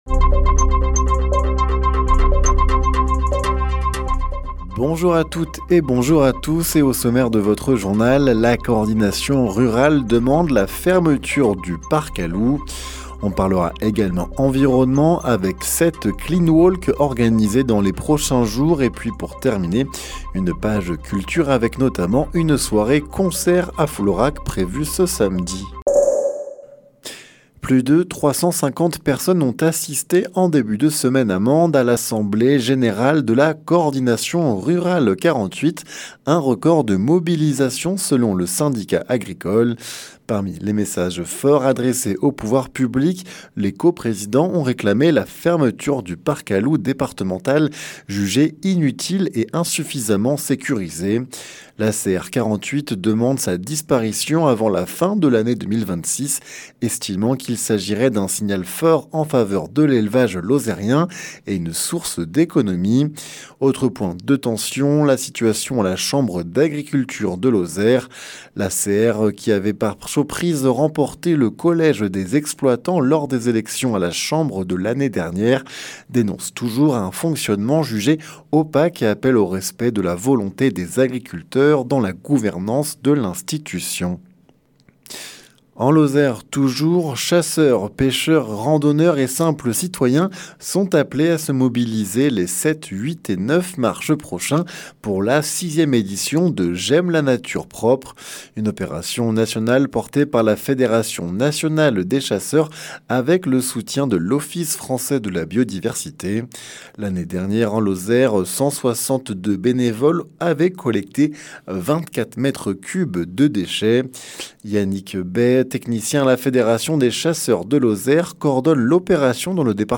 Le journal sur 48FM